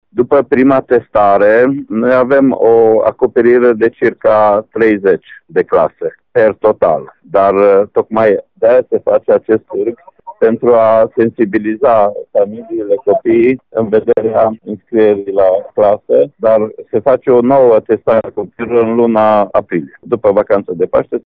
Inspectorul şcolar general al judeţului Mureş, Ştefan Someşan.